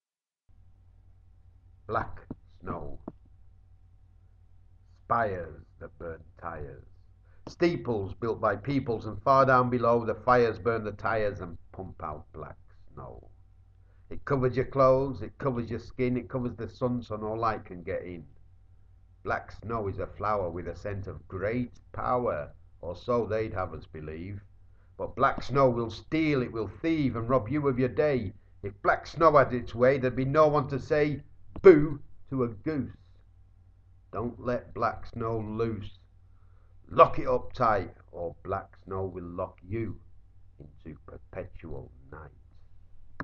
Spoken Word